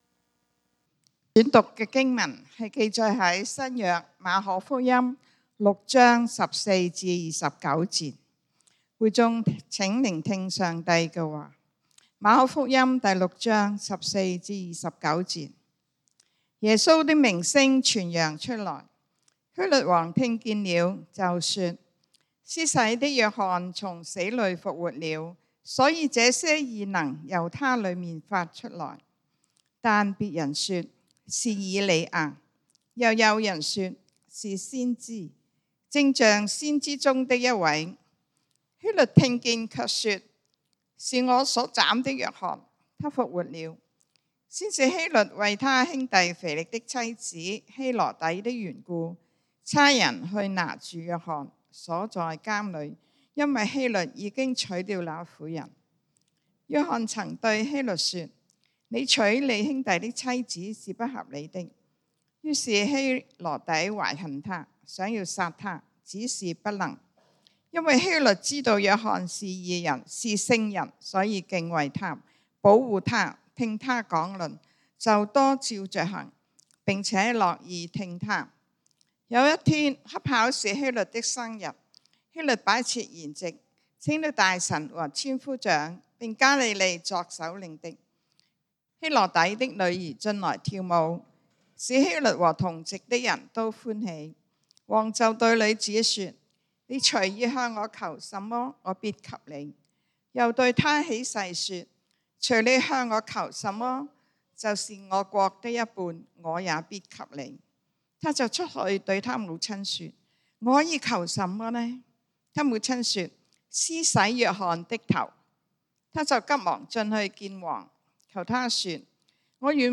講道經文：《馬可福音》Mark 6:14-29 本週箴言：《約翰福音》John 17:4 耶穌說：「我在地上已經榮耀祢，祢所托付我的事，我已成全了。」